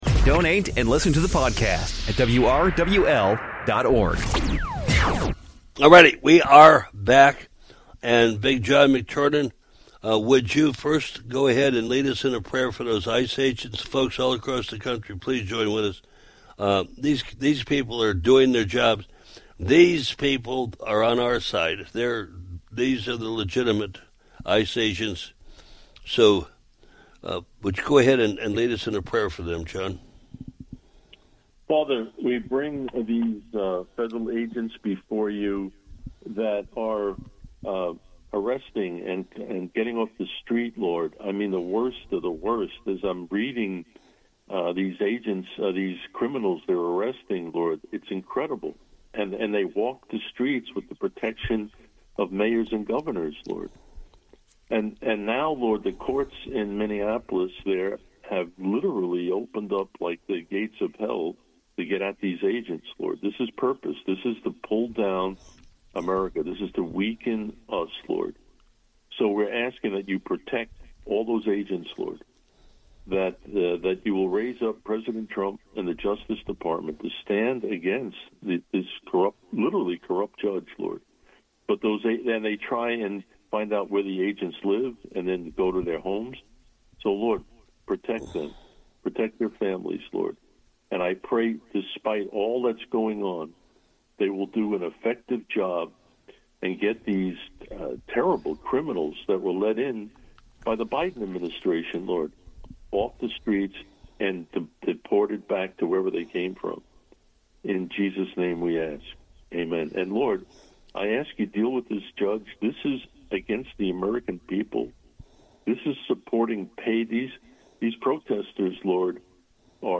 A Prayer said for the Families of ICE